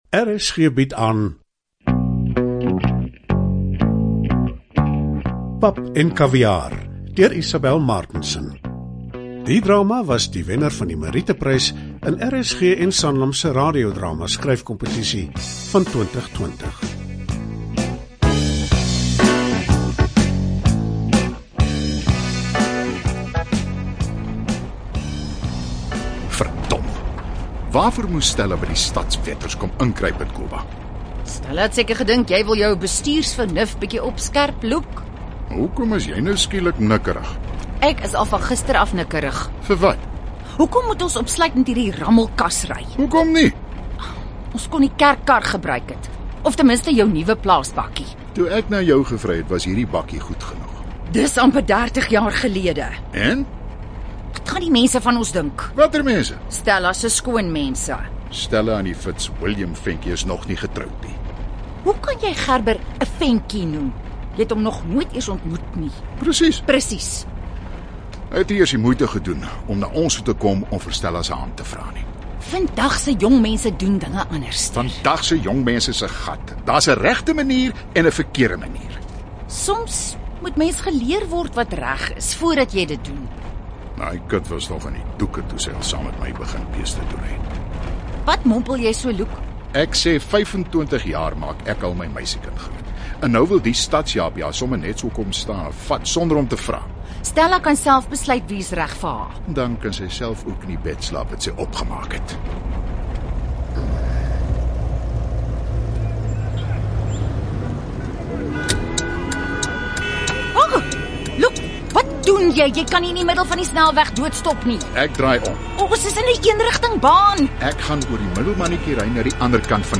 'Pap en Kaviaar' is 'n komiese drama
Die klugtige drama was die wenner van die merieteprys in RSG en Sanlam se radiodrama-skryfkompetisie van 2020 toe sowat 380 inskrywings vir die kompetisie ontvang is.